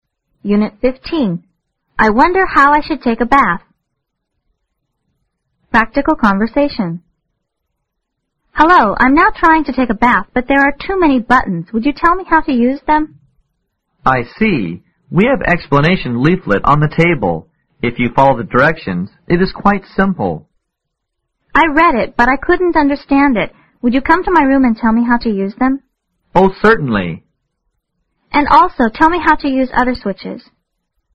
Practical conversation